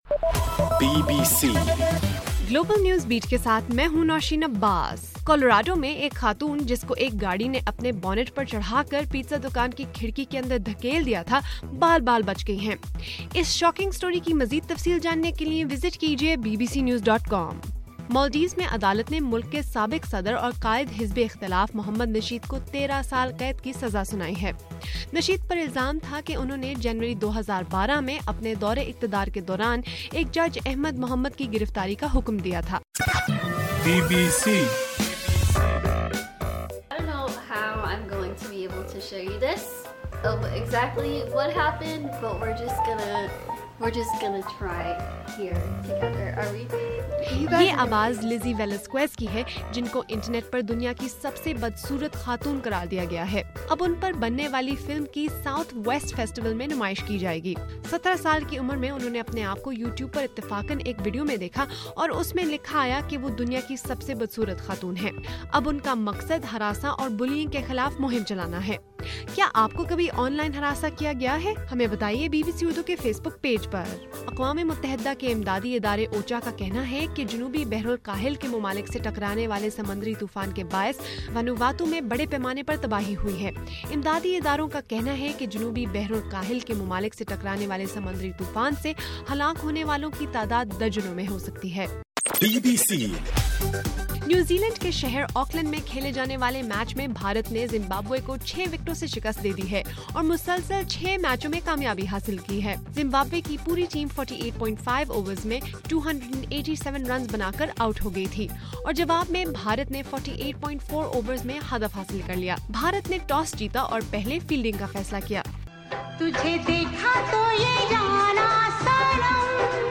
مارچ 14: رات 11 بجے کا گلوبل نیوز بیٹ بُلیٹن